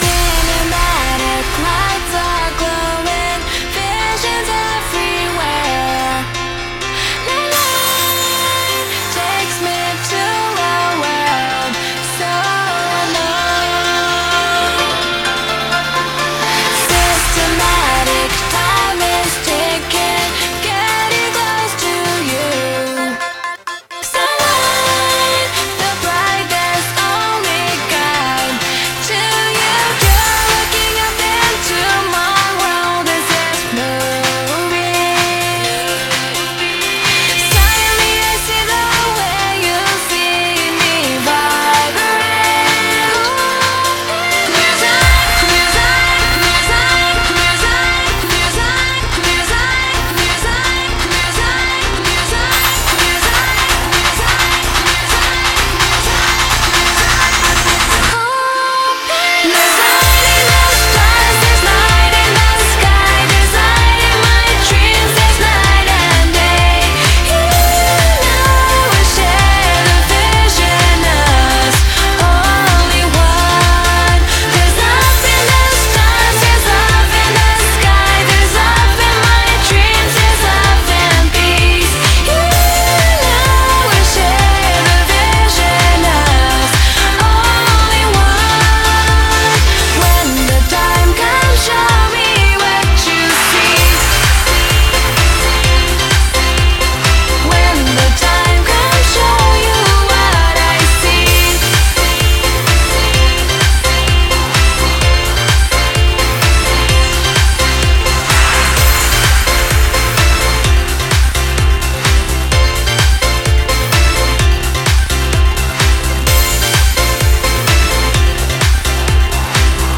BPM128
Audio QualityPerfect (High Quality)
Comments[J-POP]